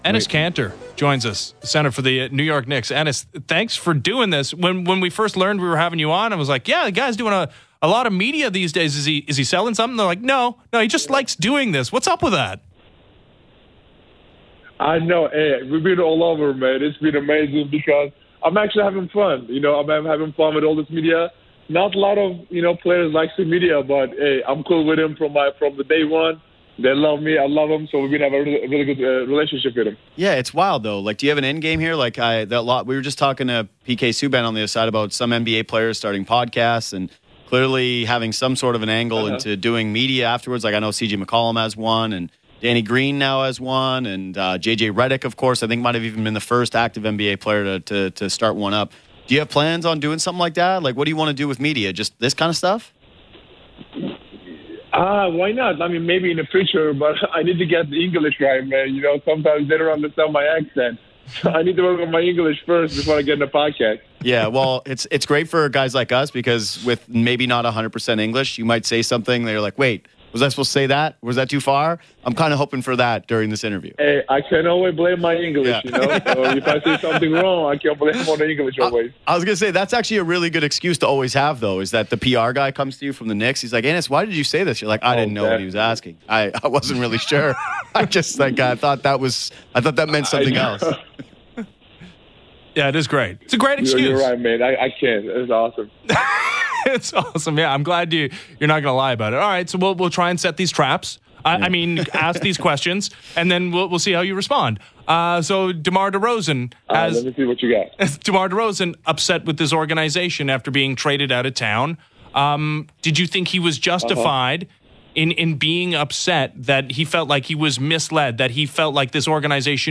When the seven-footer joined Good Show on Sportsnet 590 The FAN on Tuesday afternoon, he didn’t pull any punches when asked his opinion on the biggest hoops story in Canada — the DeMar DeRozan trade.
It’s an entertaining, wide-ranging interview and certainly worth a listen: Count Enes Kanter among those unhappy with DeRozan trade July 31 2018